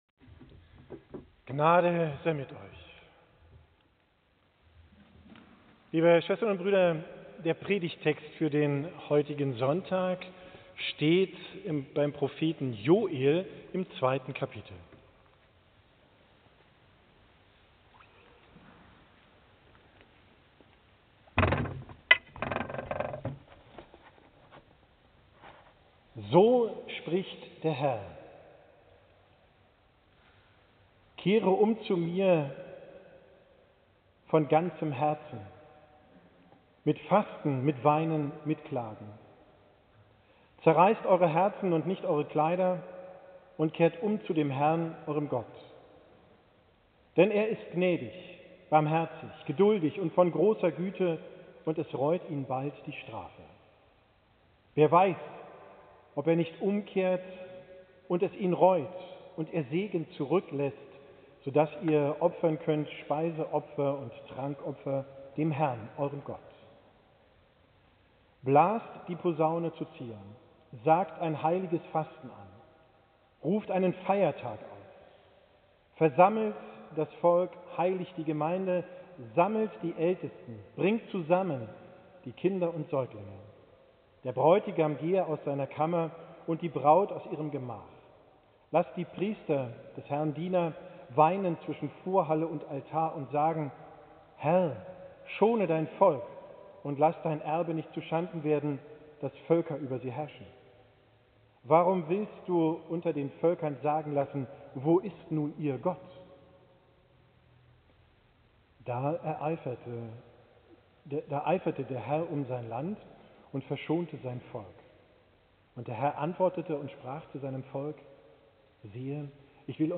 Predigt vom Sonntag, den 9.